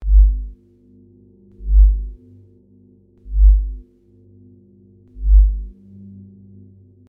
Free SFX sound effect: Jedi Timer.